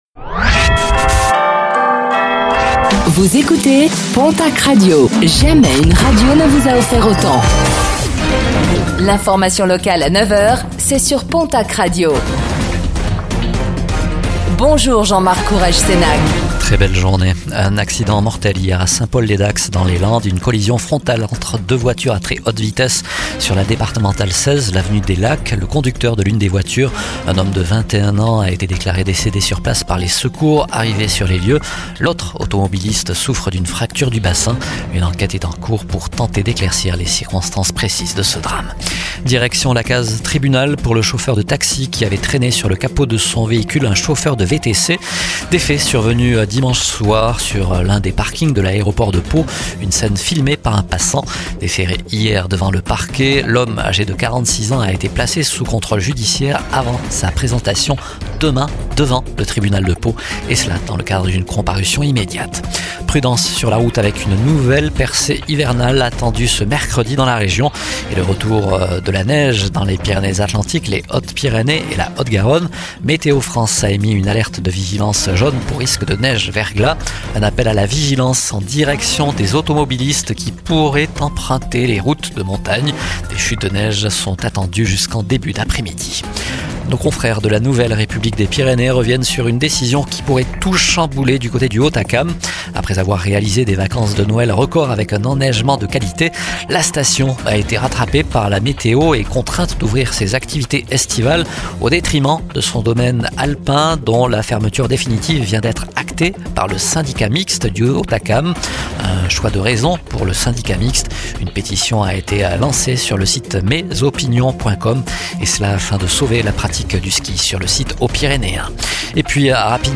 09:05 Écouter le podcast Télécharger le podcast Réécoutez le flash d'information locale de ce mercredi 16 avril 2025